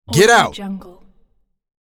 Police Officer Stock Lines Vocal Male Get Out Sound Button - Free Download & Play